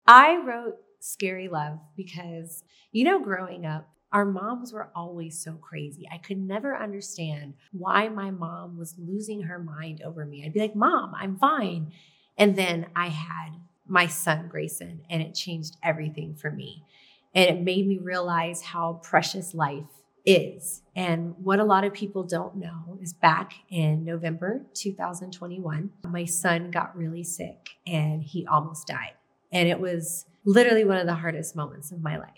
Audio / Mickey Guyton talks about why she wrote "Scary Love."